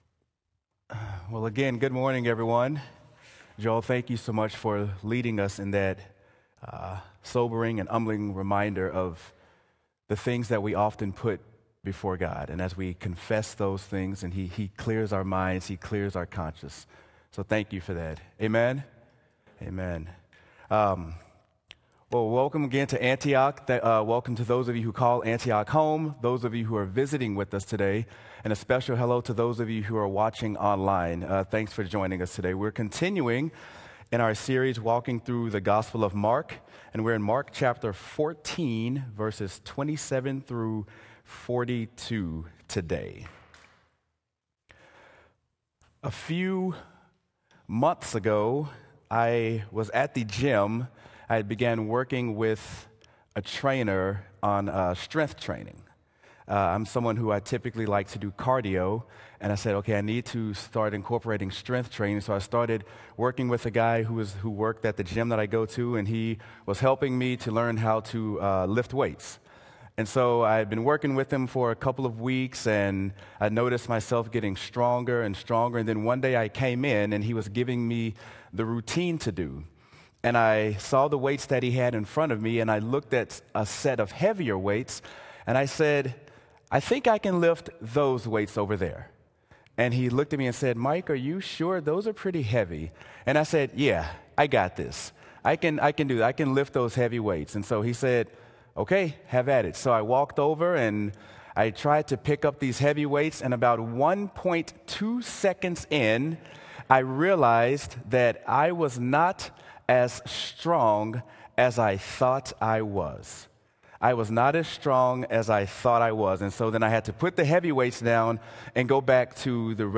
Sermon: Mark: The Problem with Pride | Antioch Community Church - Minneapolis
sermon-mark-the-problem-with-pride.m4a